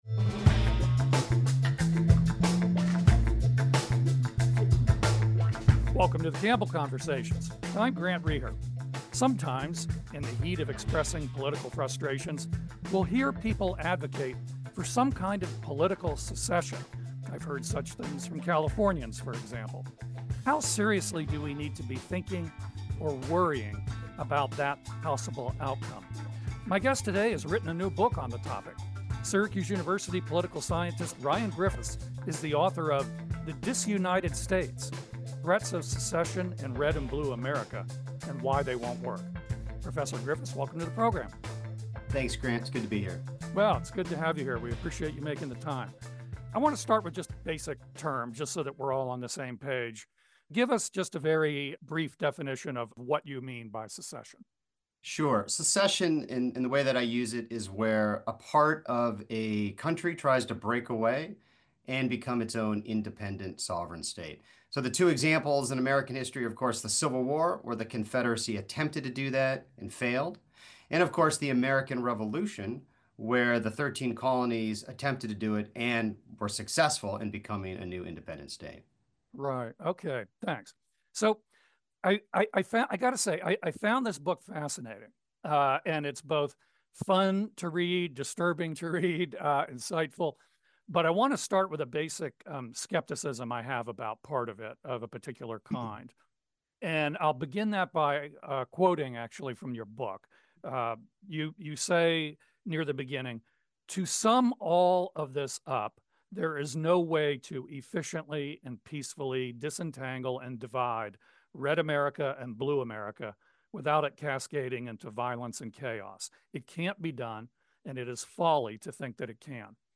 leads a conversation with a notable guest. Guests include people from central New York -- writers, politicians, activists, public officials, and business professionals whose work affects the public life of the community -- as well as nationally prominent figures visiting the region to talk about their work.